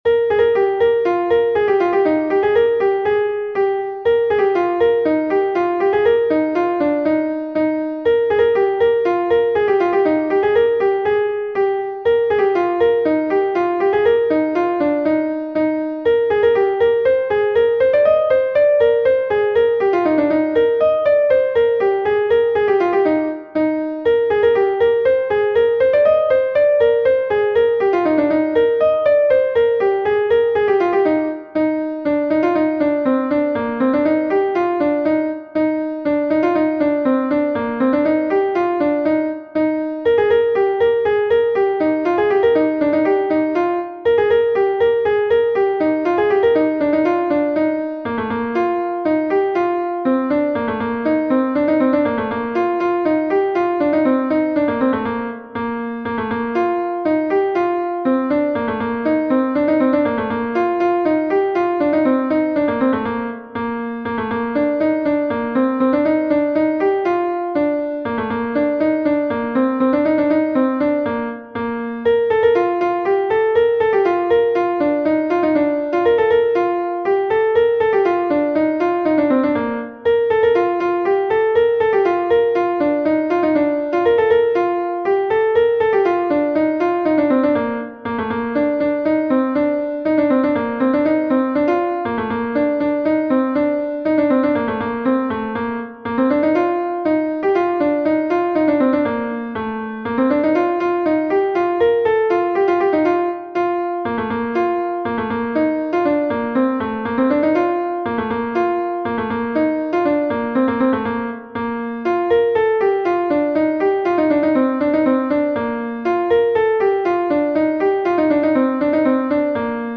Gavotenn Sant-Tudal I is a Gavotte from Brittany